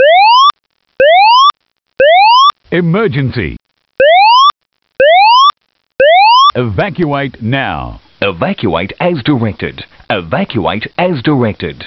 Announcement Tones
“Verbal 118” are the new AS1690.4 ISO T3 tones with standard voice message and extra instructions “evacuate as directed” incorporated